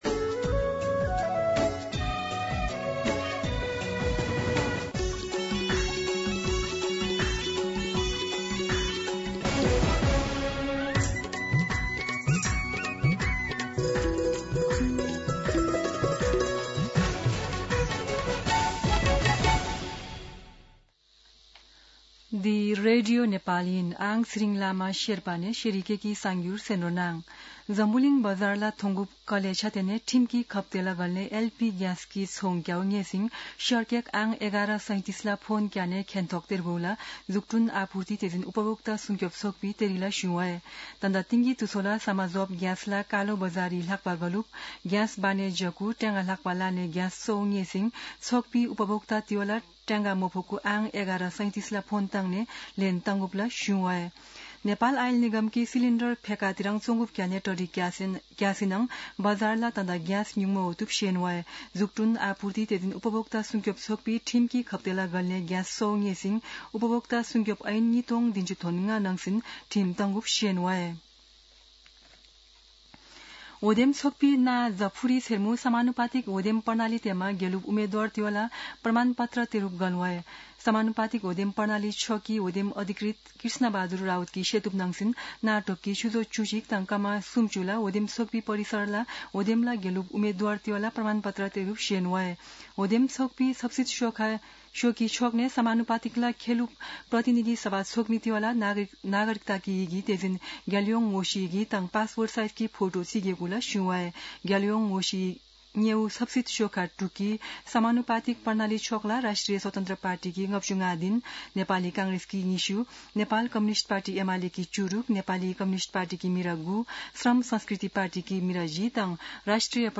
शेर्पा भाषाको समाचार : ३ चैत , २०८२
sherpa-News-03.mp3